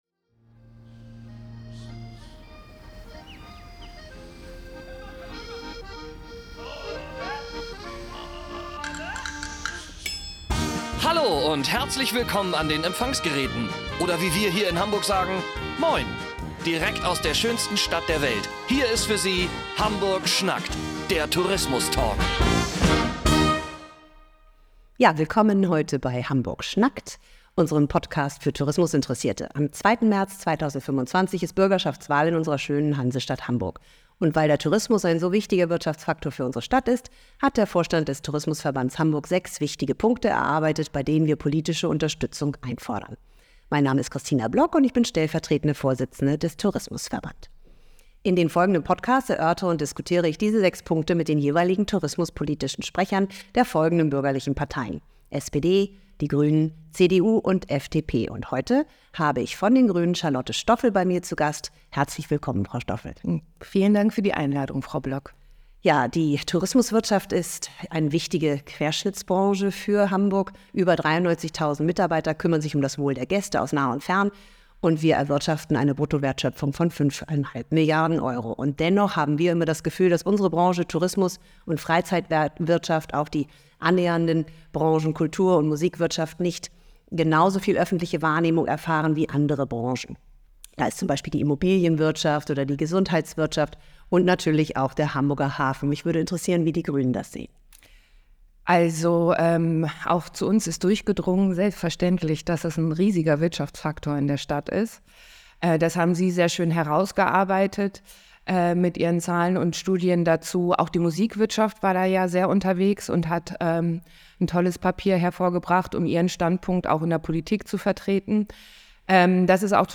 Hamburg schnackt – Der Tourismus Talk